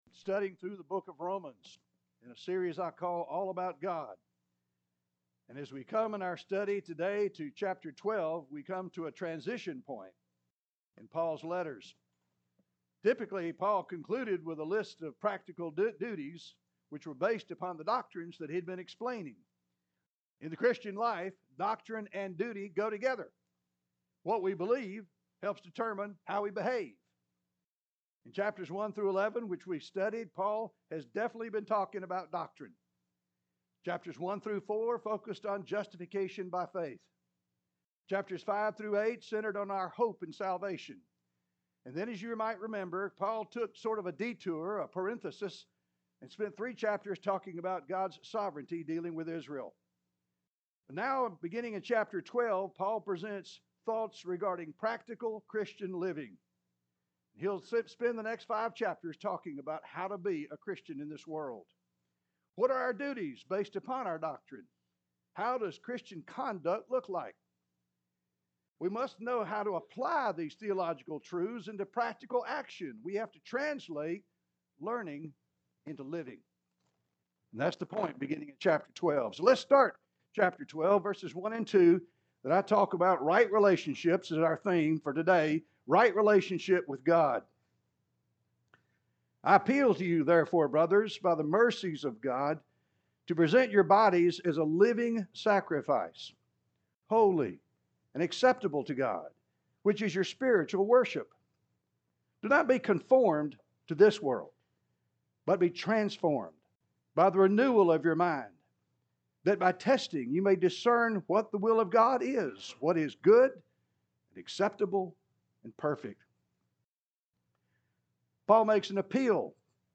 (Sermon Series)